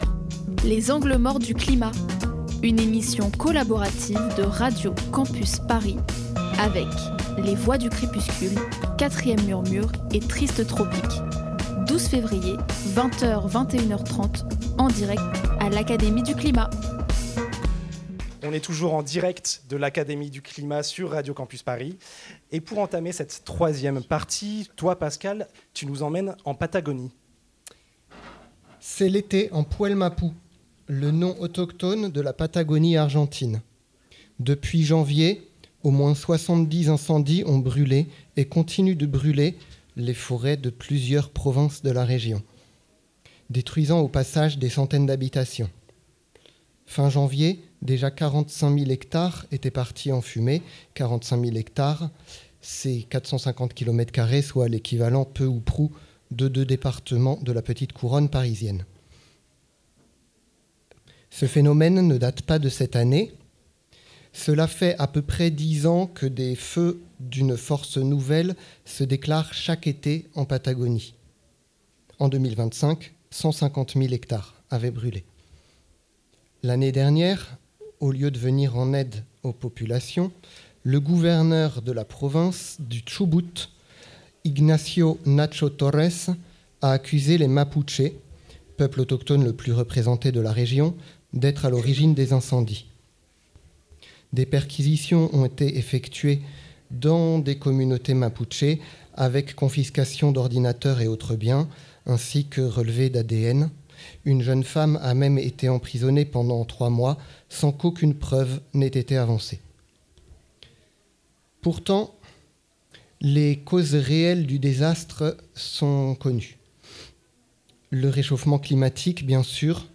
Type Entretien
Ce podcast reprend la troisième partie de l'entretien réalisé en public le 12 février 2026 à l'Académie du climat, en collaboration avec les émissions Triste tropique et Quatrième murmure.